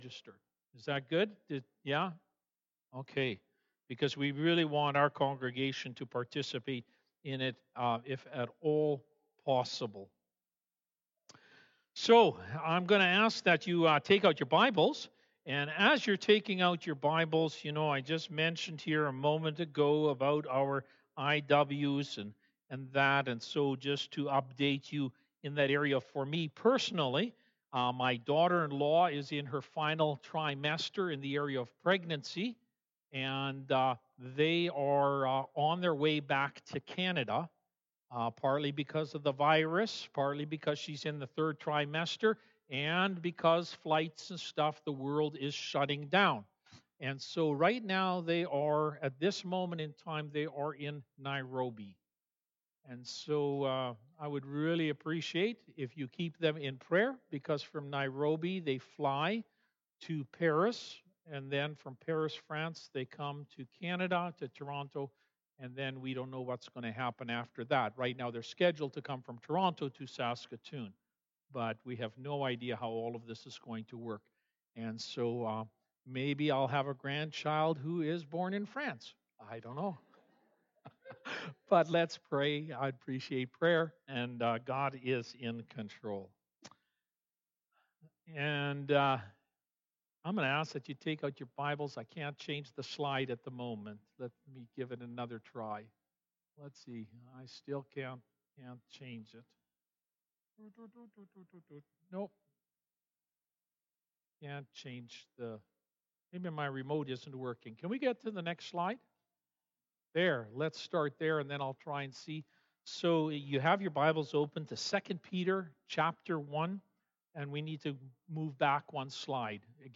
Sermons | Westgate Alliance Church